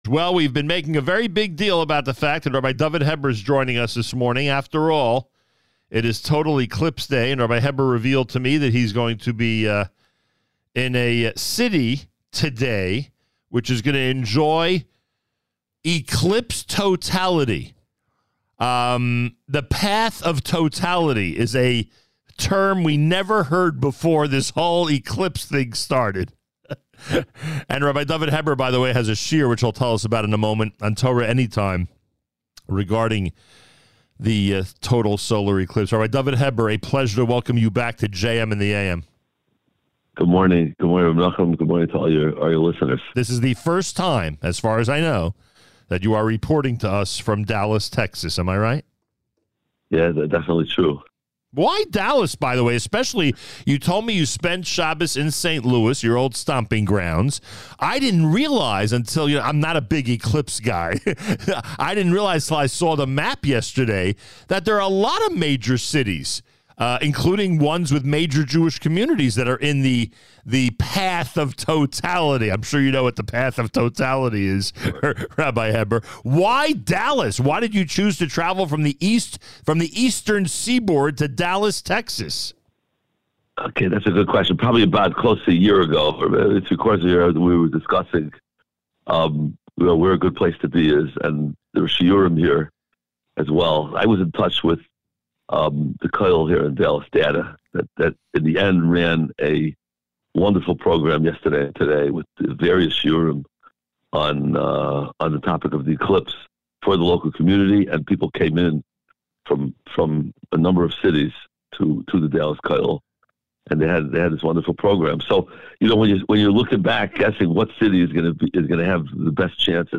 live via telephone, from Dallas, TX on the path of totality for today’s historic solar eclipse. It was a fascinating look at this rare natural phenomenon in the context of Torah/the Jewish calendar.